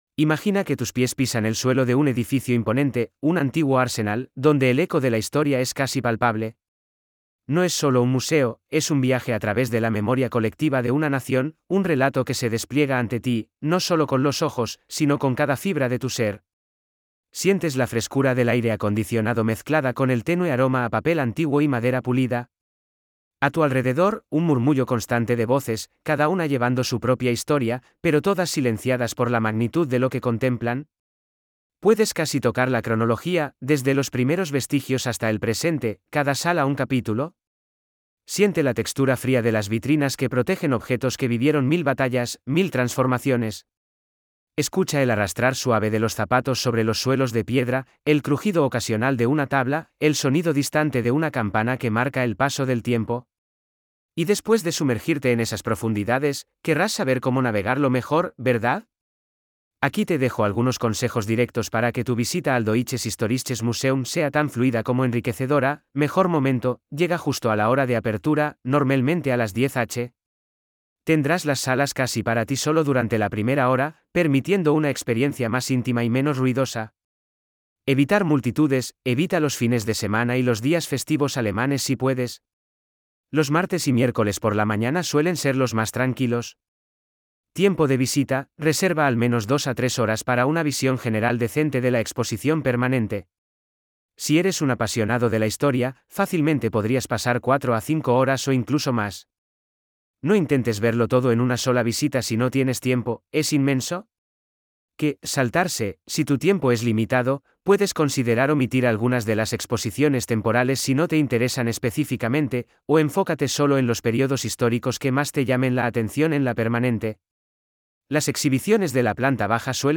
🎧 Guías de audio disponibles (2) Guía de Experiencia Emocional (ES) 🔗 Abrir en una nueva pestaña Información práctica (ES) 🔗 Abrir en una nueva pestaña